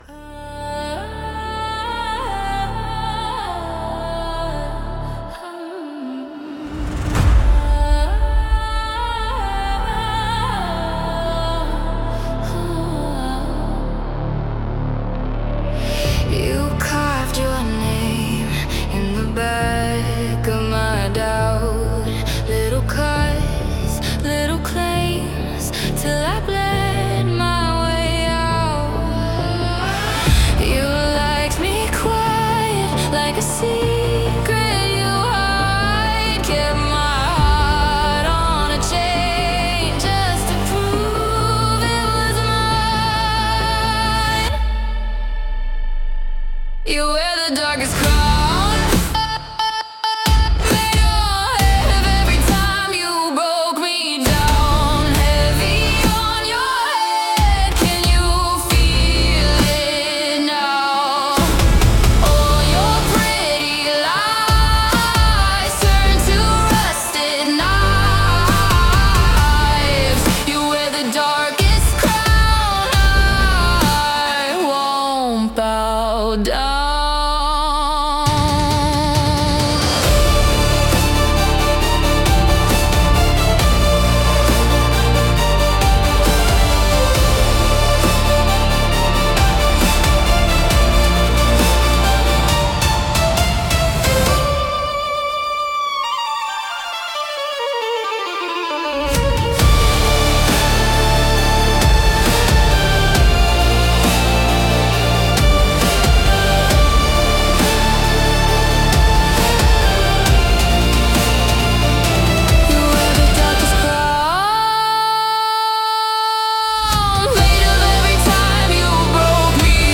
壮大なスケール感を持つ、ダーク・オーケストラ・ポップ。
ただ暗いだけではなく、芯のある「かっこよさ」が際立っており、まるで闇の女王がフロアに降臨したかのような存在感を放ちます。
重厚な音に合わせて、強い目線やポーズで観客を射抜くような、ドラマチックでクールな演技に最適です。